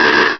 Cri de Parecool dans Pokémon Rubis et Saphir.